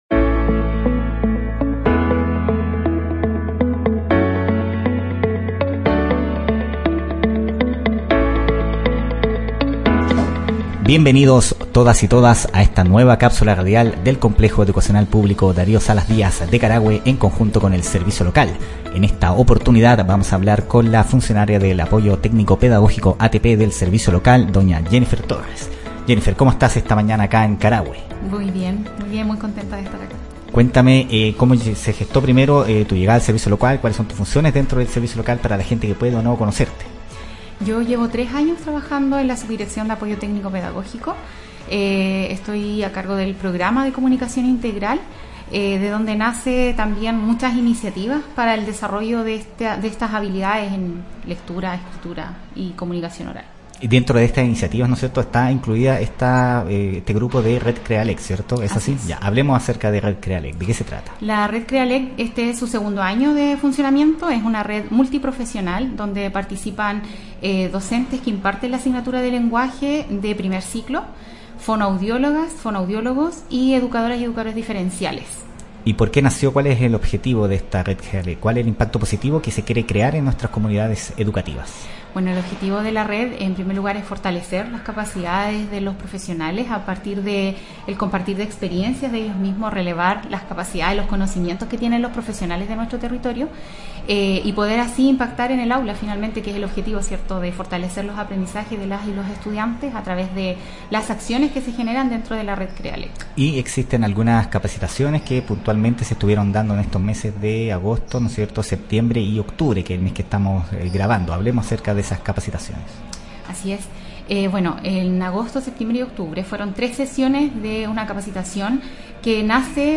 El Servicio Local de Educación Pública Costa Araucanía (SLEPCA) y el Complejo Educacional Darío Salas Díaz de Carahue, se unieron para realizar Cápsulas Radiales en el Locutorio del establecimiento educacional.